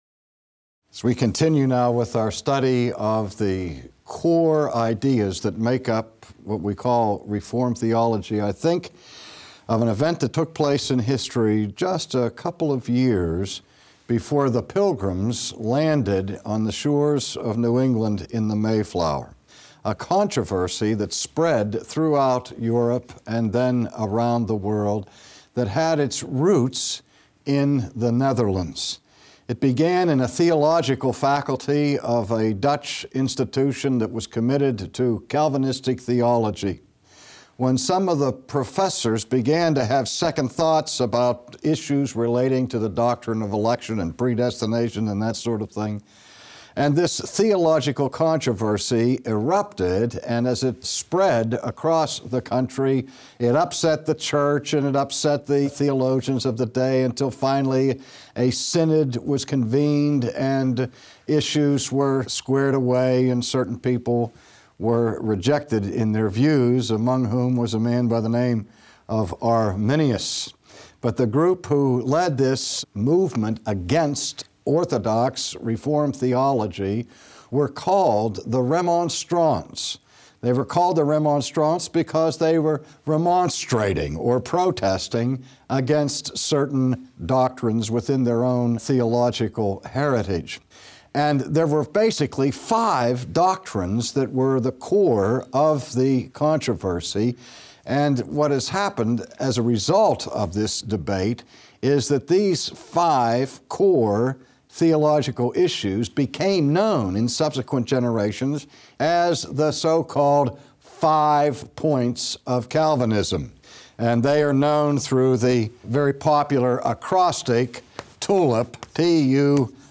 Tim Keller, Pastor of Redeemer Presbyterian Church in NYC, a champion for gospel and gospel-centered ministry expounds Isaiah 53 and 54, to explain what the gospel does.
This sermon beautifully pictures the kind of people the gospel produces. The Gospel Listen | Download